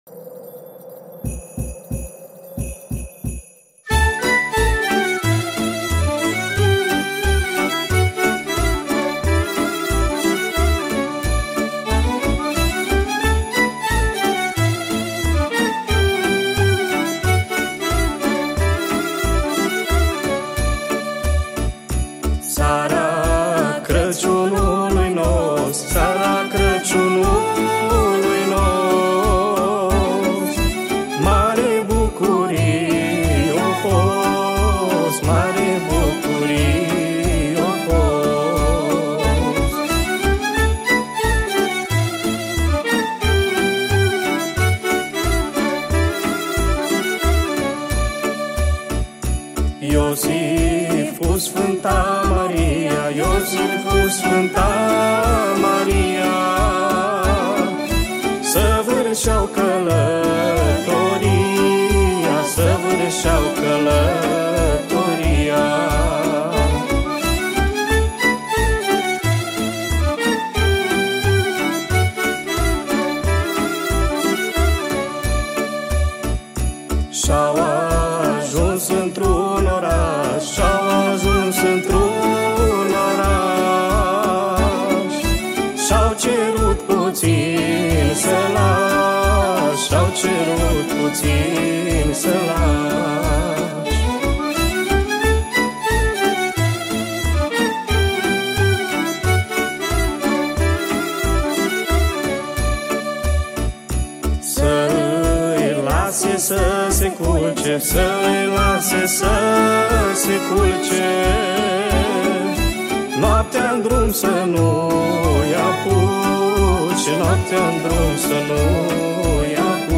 Data: 02.10.2024  Colinde Craciun Hits: 0
Colinda Traditionala